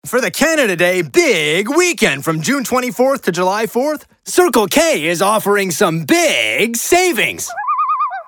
Publicité (Circle K) - ANG